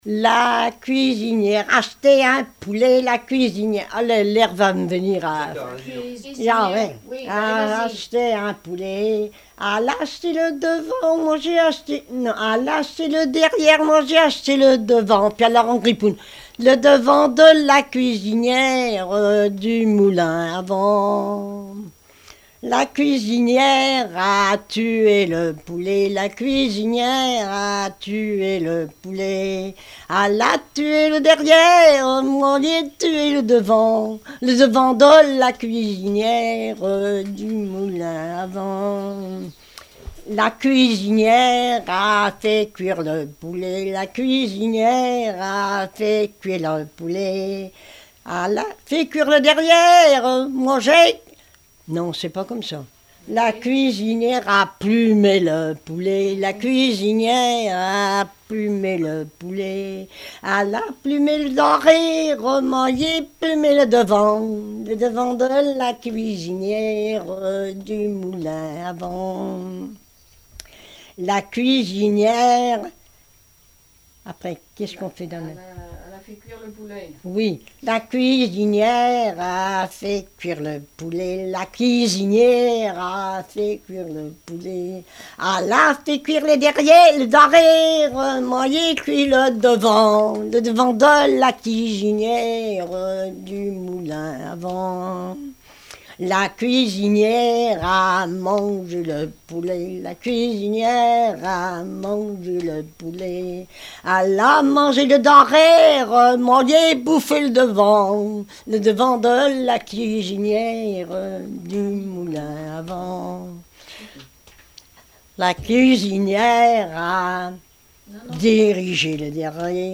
Genre énumérative
témoigneges et chansons populaires
Pièce musicale inédite